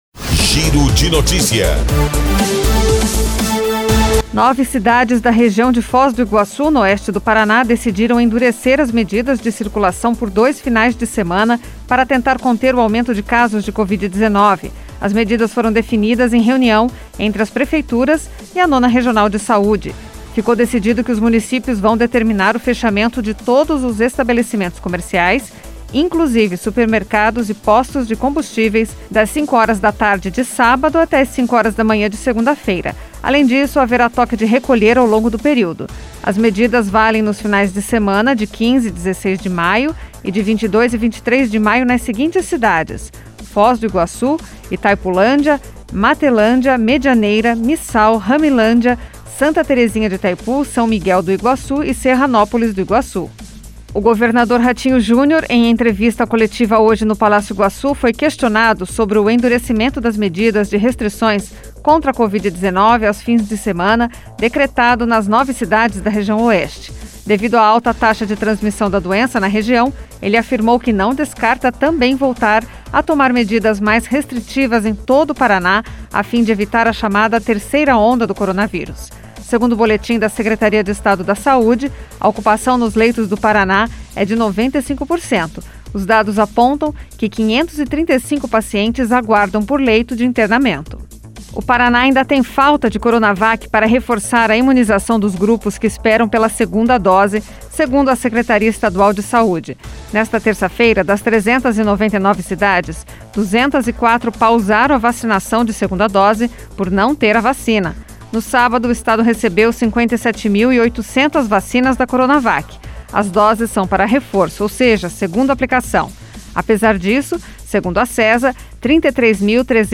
Giro de Notícias Tarde COM TRILHA